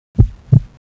adrenaline_heartbeat.wav